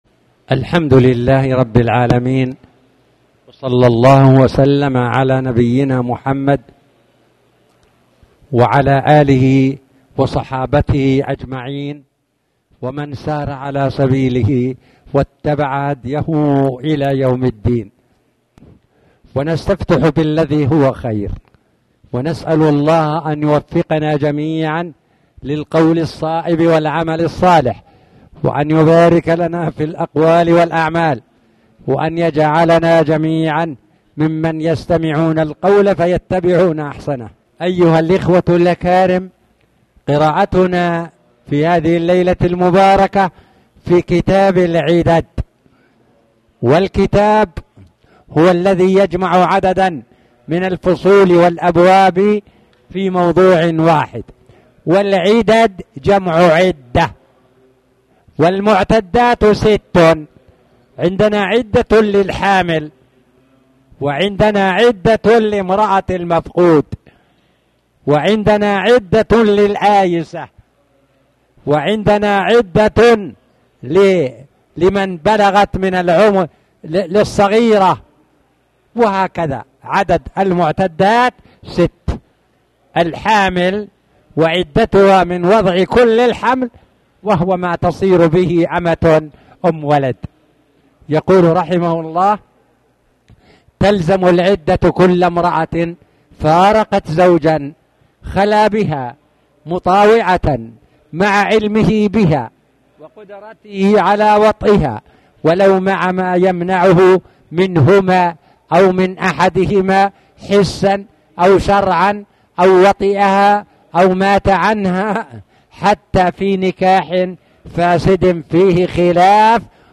تاريخ النشر ١٣ محرم ١٤٣٩ هـ المكان: المسجد الحرام الشيخ